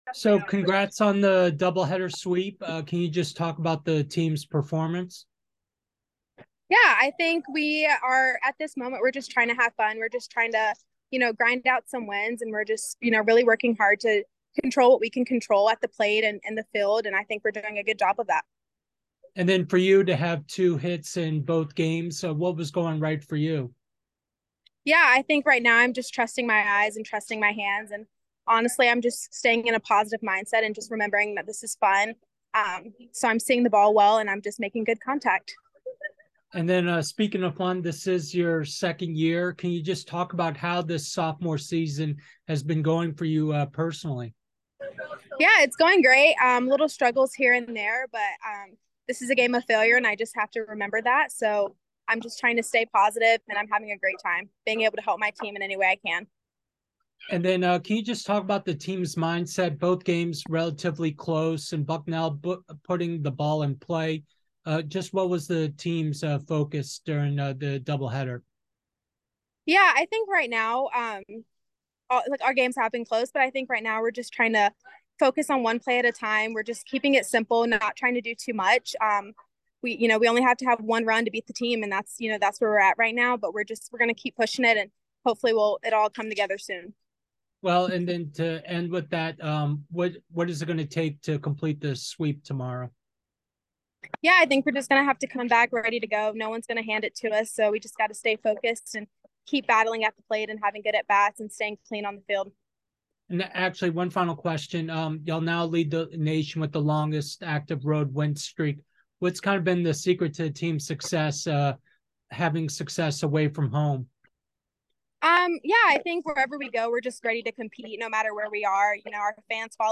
Softball / Bucknell DH Postgame Interview